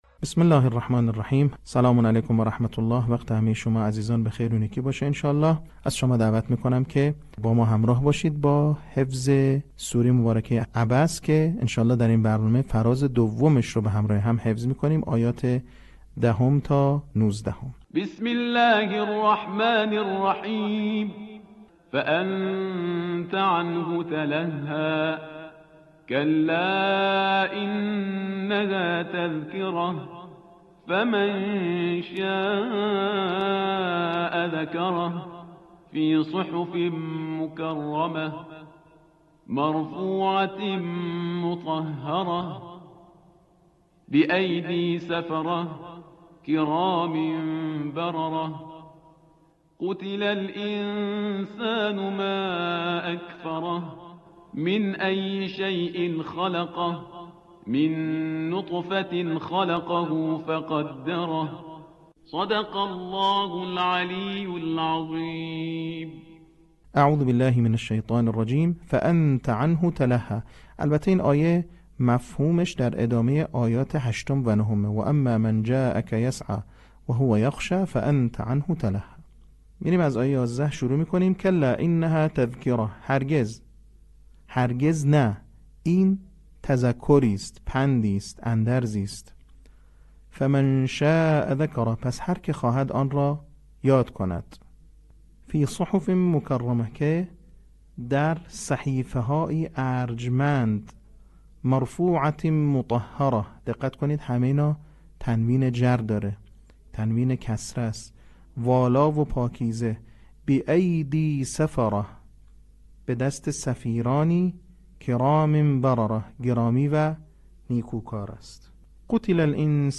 صوت | بخش دوم آموزش حفظ سوره عبس